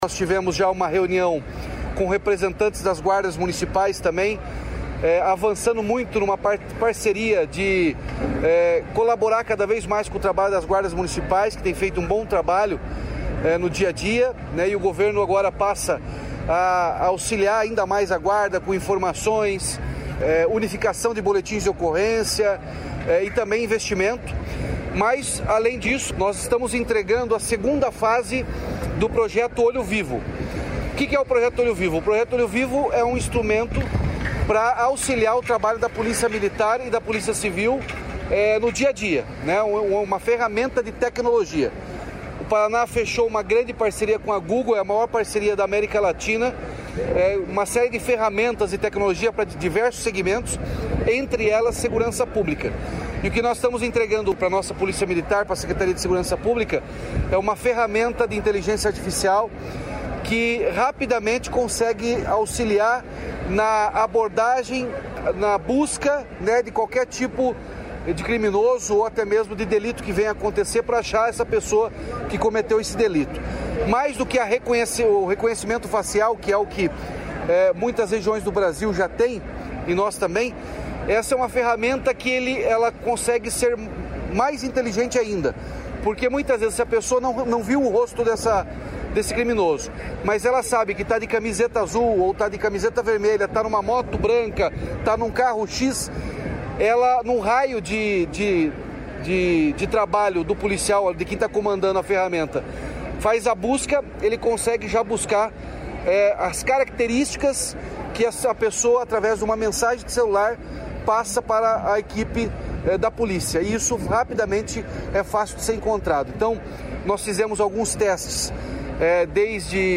Sonora do governador Ratinho Junior sobre o reforço da Segurança Pública com o Olho Vivo